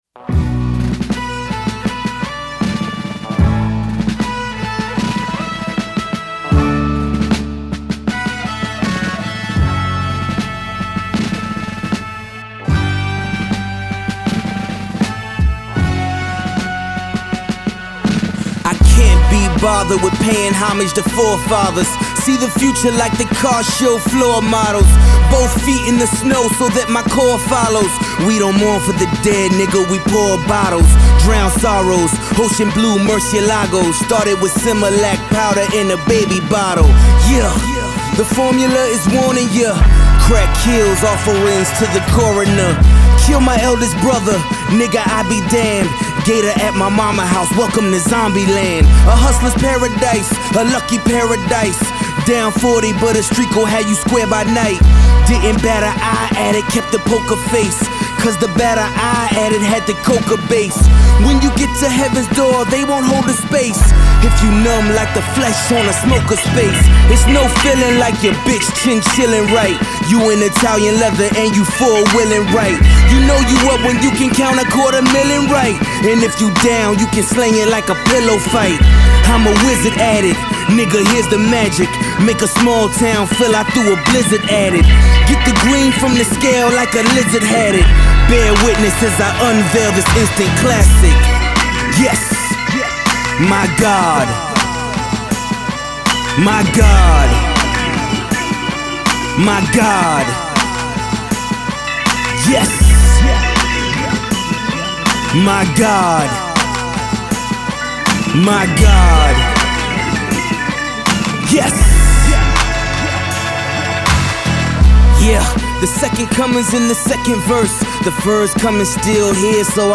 there’s no doubt this Virginia emcee can rhyme.